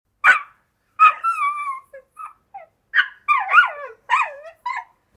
Dog Crying And Yelping Sound Effect Download: Instant Soundboard Button
Dog Sounds3,190 views